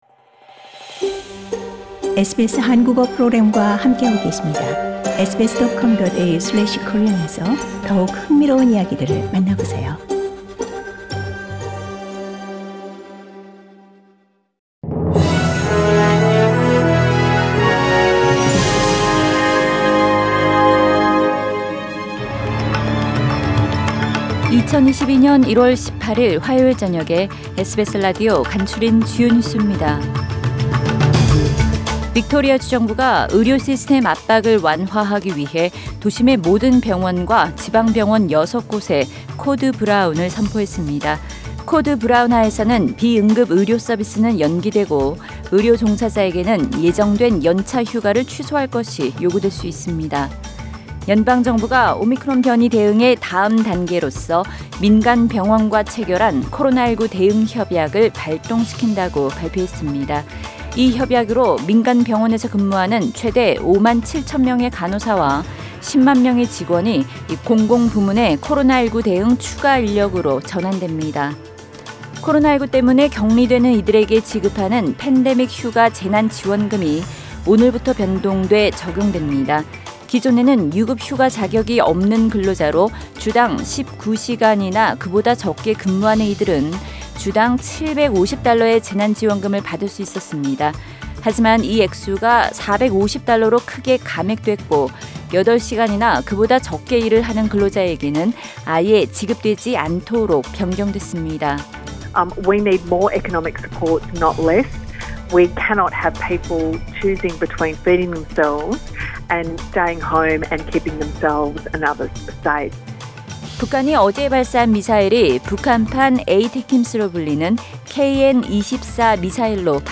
SBS News Outlines…2022년 1월 18일 저녁 주요 뉴스
2022년 1월 18일 화요일 저녁의 SBS 뉴스 아우트라인입니다.